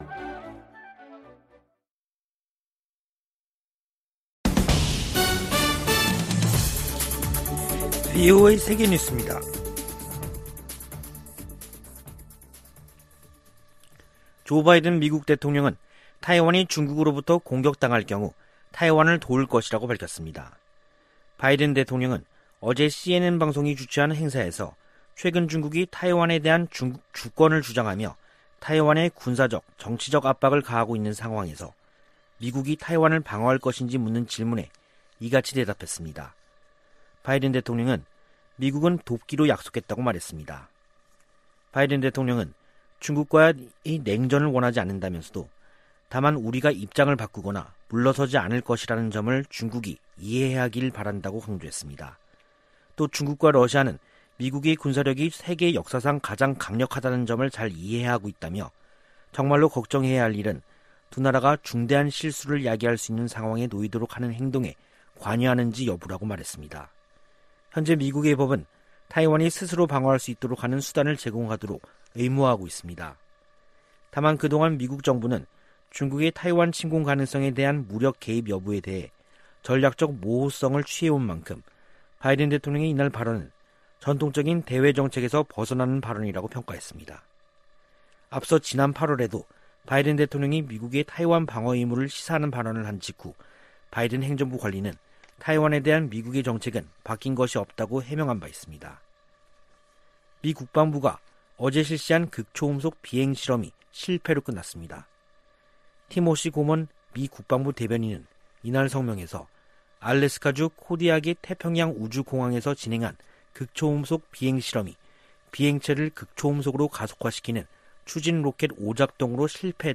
VOA 한국어 간판 뉴스 프로그램 '뉴스 투데이', 2021년 10월 22일 2부 방송입니다. 북한이 가능한 모든 영역에서 핵 개발에 전력을 다하고 있다고 국제원자력기구(IAEA) 사무총장이 지적했습니다. 미국은 제재 사용에 관해 동맹· 파트너 국가들과의 협의와 협력을 중시할 것이라고 미 재무부 부장관이 밝혔습니다. 북한이 여전히 대량살상무기 기술 이전 역할을 하고 있다고 낸시 펠로시 미 하원의장이 지적했습니다.